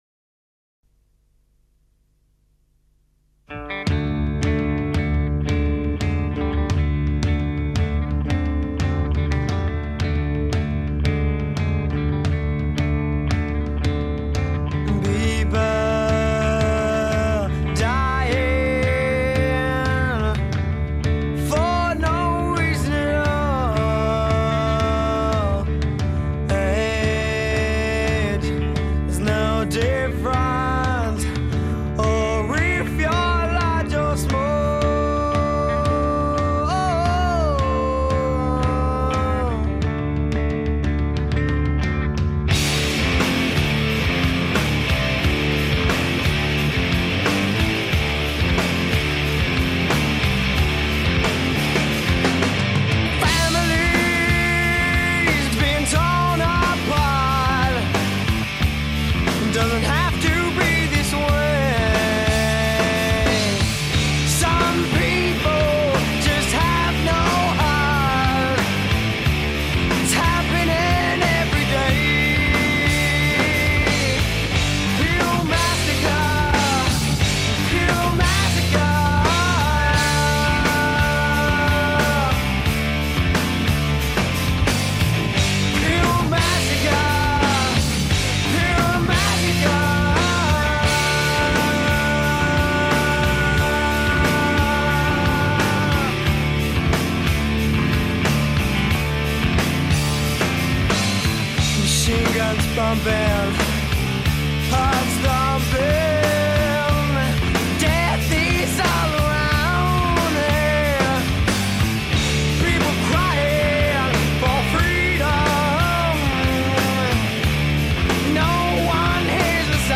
گرانج راک
Grunge Rock, Alternative Rock